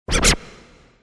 break.mp3